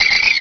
pokeemerald / sound / direct_sound_samples / cries / plusle.aif